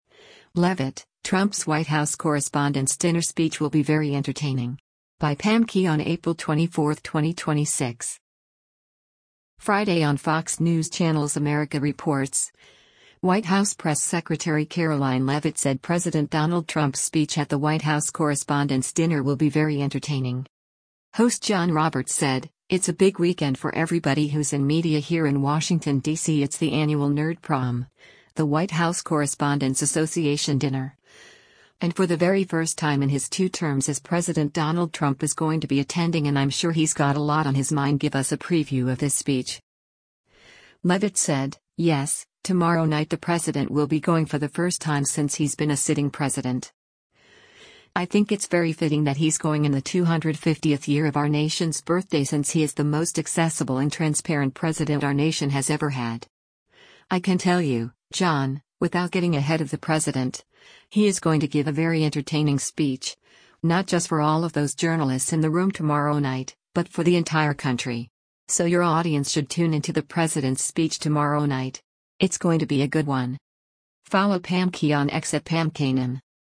Friday on Fox News Channel’s “America Reports,” White House press secretary Karoline Leavitt said President Donald Trump’s speech at the White House Correspondents’ dinner will be “very entertaining.”